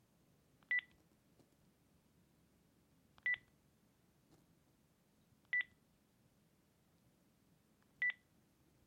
电子无绳电话 " 电子电话，按下按钮有提示音
描述：无绳电子电话按钮按下哔哔声